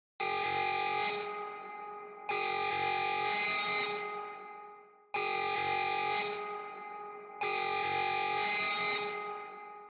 tiếng báo động ID ROBLOX sound effects free download